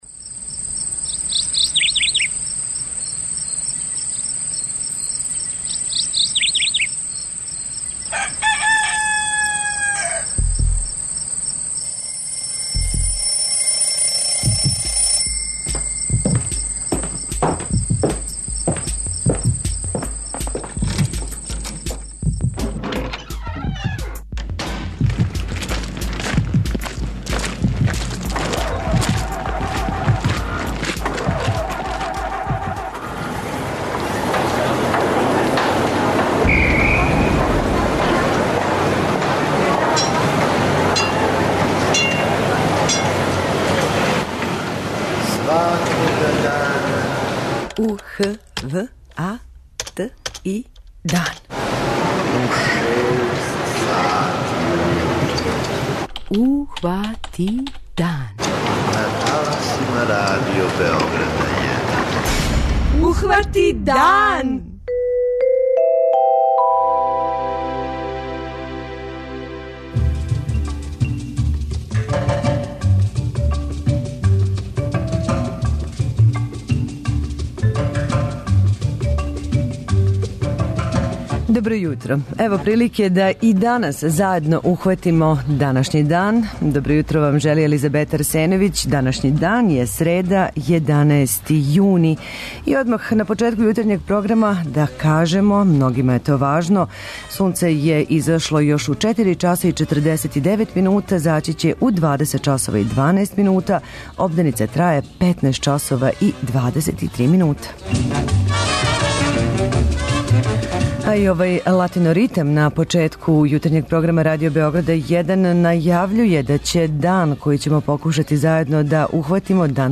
преузми : 85.90 MB Ухвати дан Autor: Група аутора Јутарњи програм Радио Београда 1!